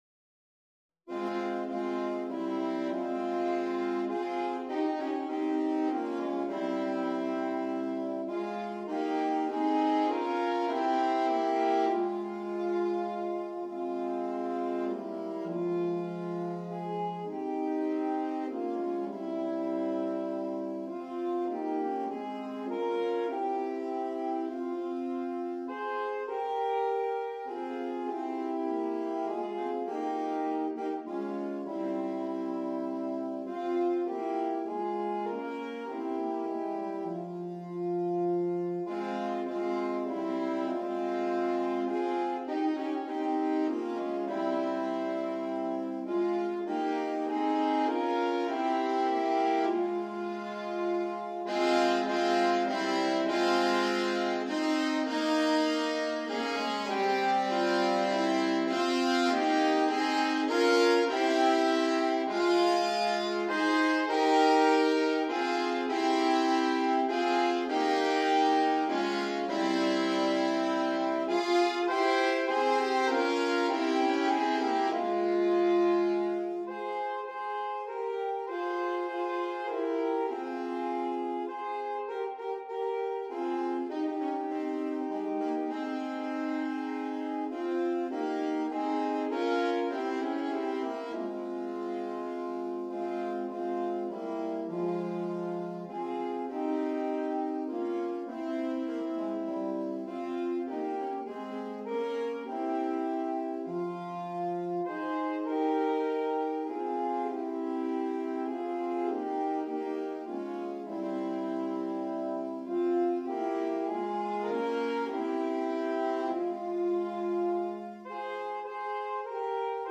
Christmas, Medieval and Renaissance